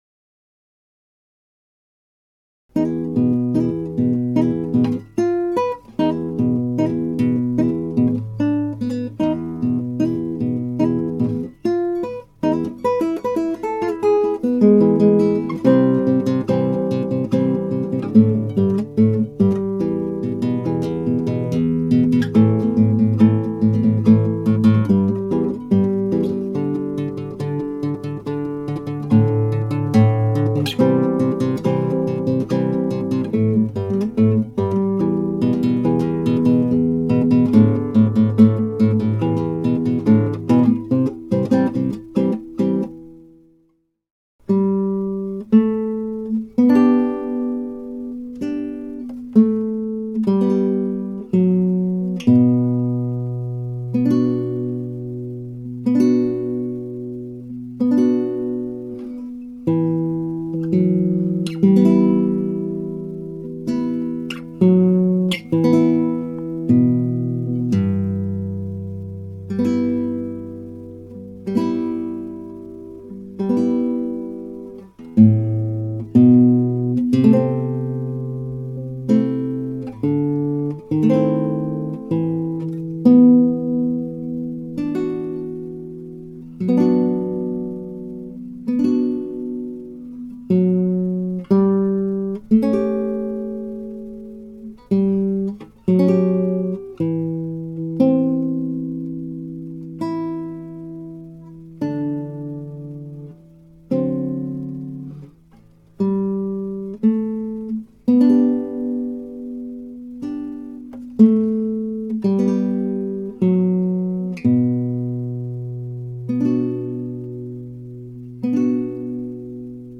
ギター演奏ライブラリ
(アマチュアのクラシックギター演奏です [Guitar amatuer play] )
この編曲は原曲をすべて写したものではなく代表的なモチーフをギター用にしたものです。
中間部のモチーフは割愛されています。